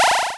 Add gate and warp sound effects.
warp.wav